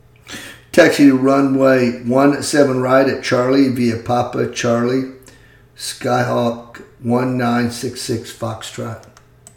Aviation Radio Calls
05a_PilotRunwayOneSevenRightCharllie.mp3